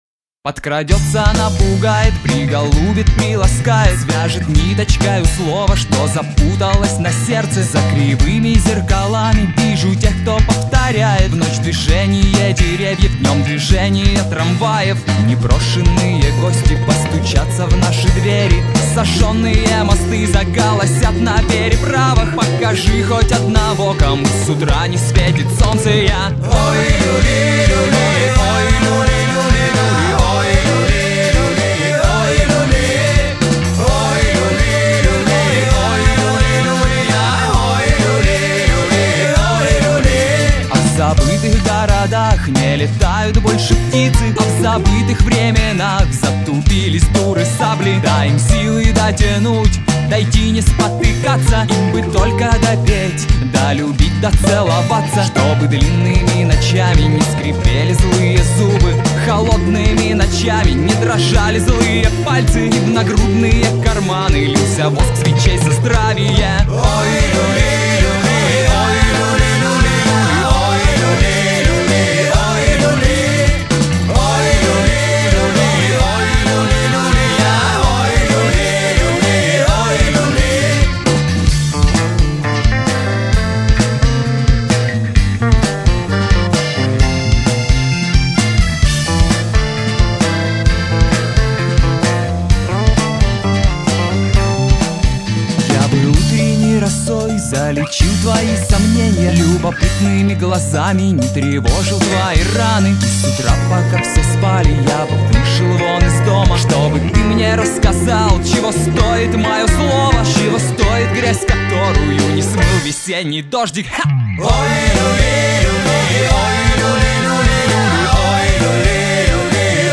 • Жанр: Рок